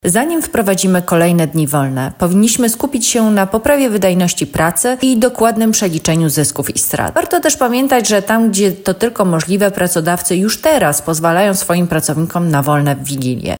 wigilia-wolna-ekspert.mp3